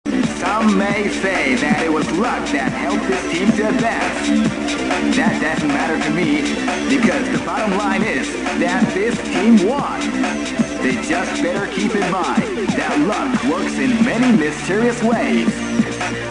The announcer tries to show his cool side...